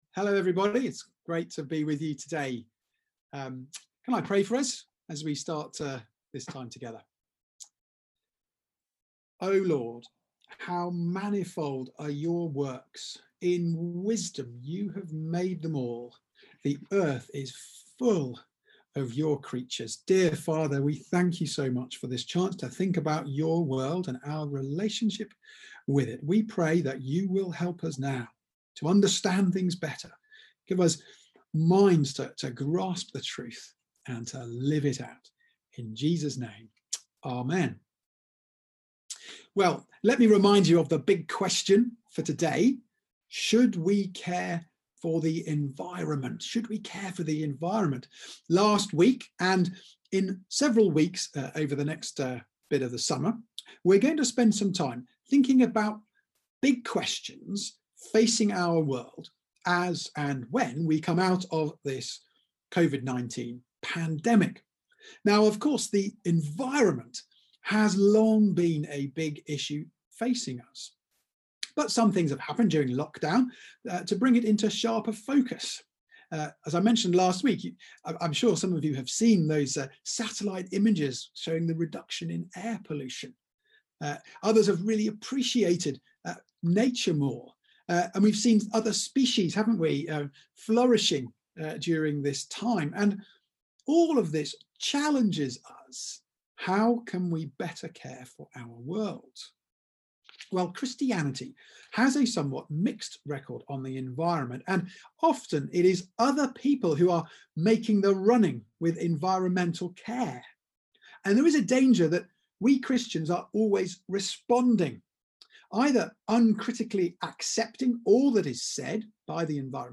Big Questions for a Post-Coronavirus World Theme: Should we Care for the Environment? Sermon Search: